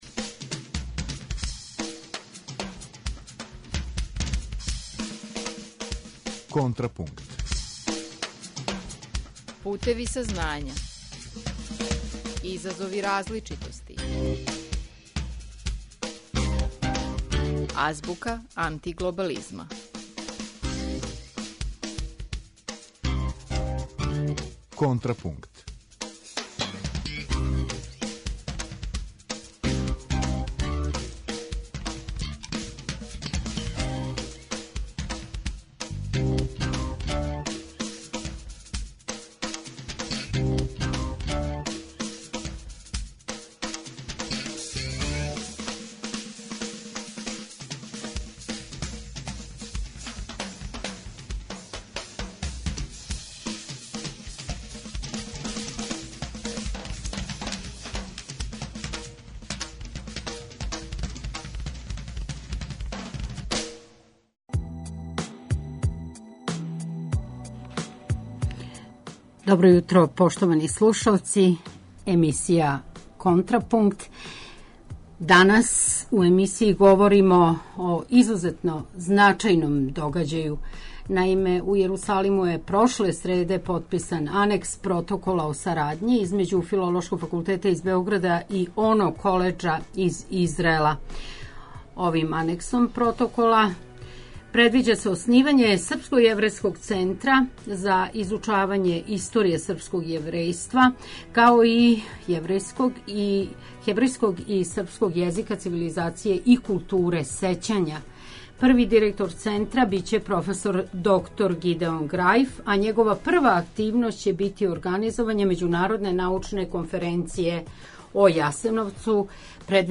Слушаоци емисије „Контрапункт" у прилици су да чују делове интервјуа